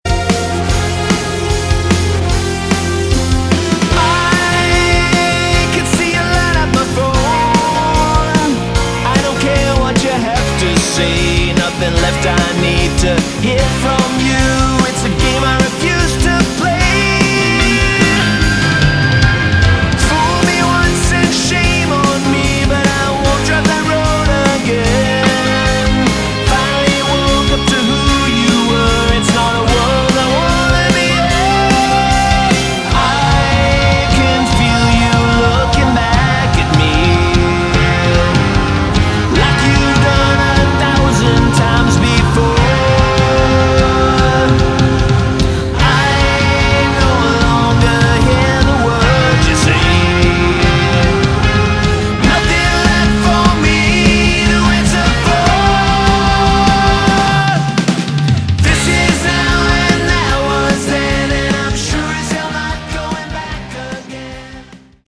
Category: Melodic Rock
vocals
bass
guitars
keyboards
drums